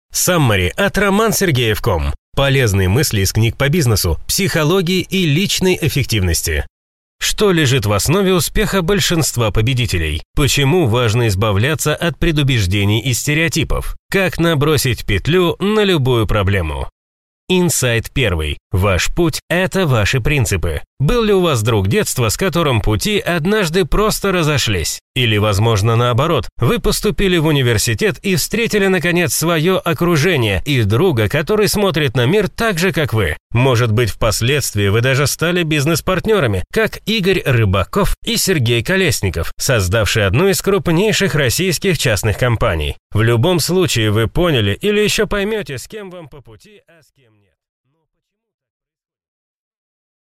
Аудиокнига Саммари на книгу «Принципы Жизнь и работа».